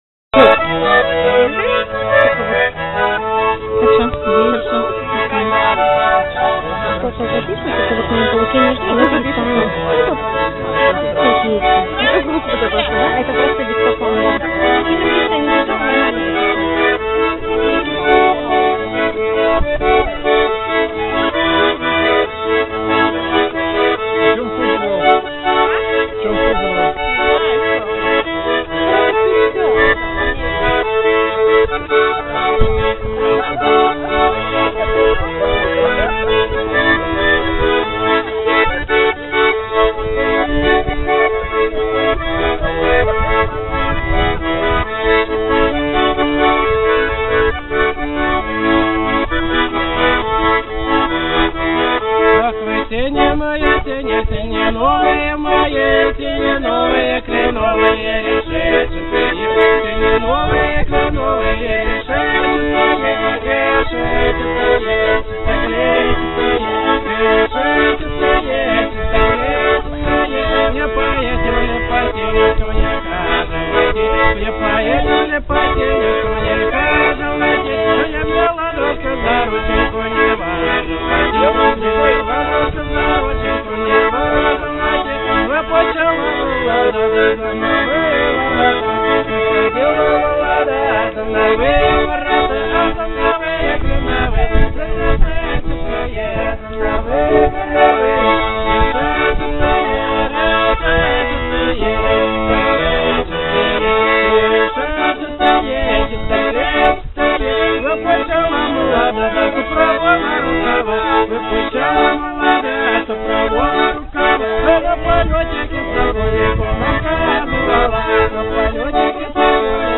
гармошка - Ах вы сени мои сени (ташшусь)